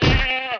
sheep_hit.wav